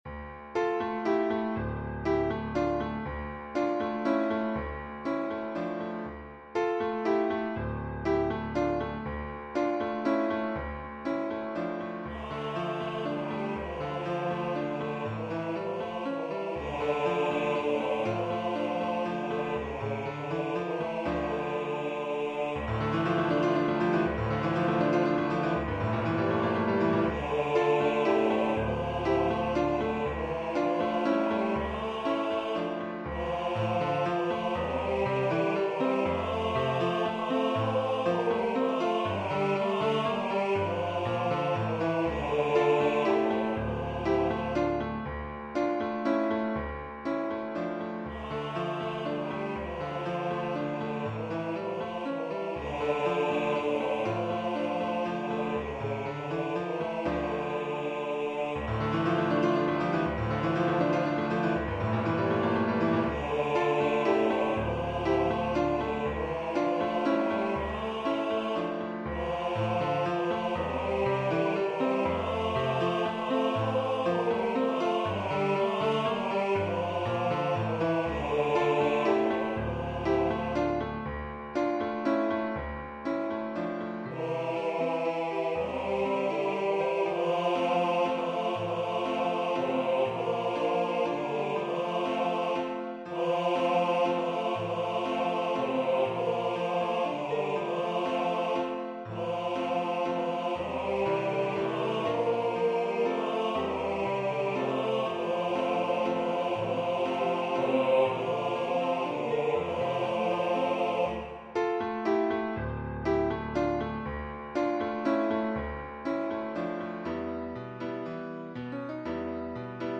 2 part choir
Christmas